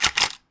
camera click_NIKON
ammo cam Camera click Click D70 Digital DSLR sound effect free sound royalty free Sound Effects